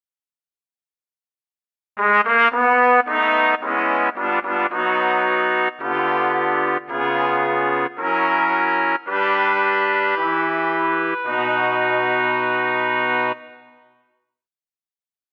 Key written in: B Major
How many parts: 4
Type: Barbershop
All Parts mix: